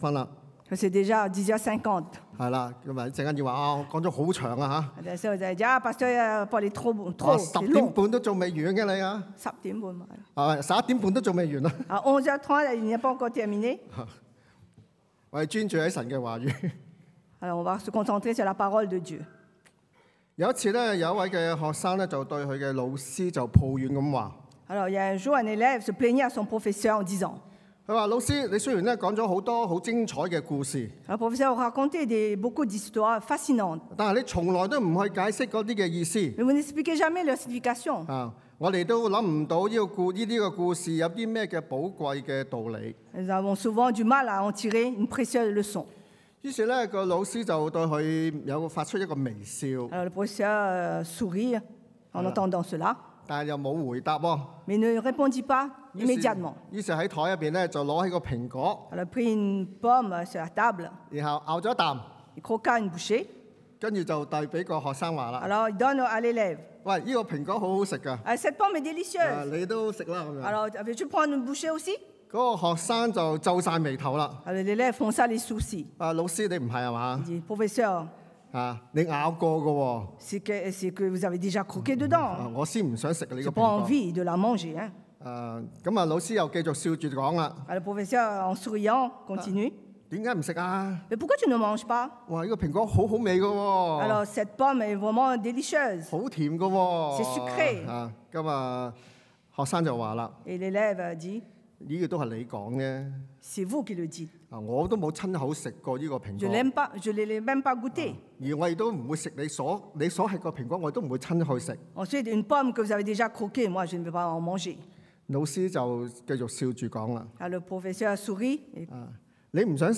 La base de la fondation 立根之本 – Culte du dimanche